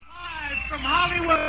FAçA DOWLOAD DE PEDAçOS DE UMAS MÚSICAS AO VIVO!!!
GUITARA
BAIXO
BATERA